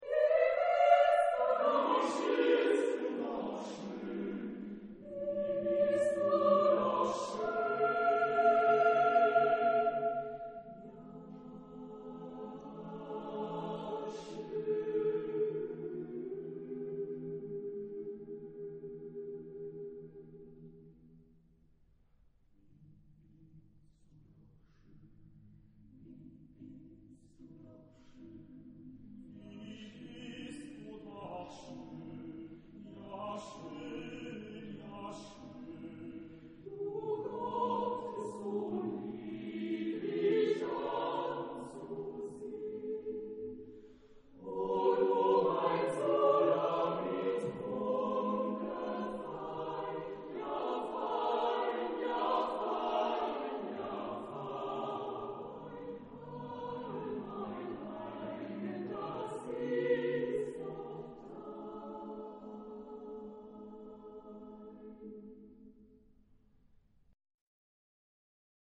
Genre-Stil-Form: Zyklus ; Chorwerk ; Psalm
Chorgattung: SATB  (4 gemischter Chor Stimmen )
Solisten: Bass (1)  (1 Solist(en))
Tonart(en): a-moll
Aufnahme Bestellnummer: Internationaler Kammerchor Wettbewerb Marktoberdorf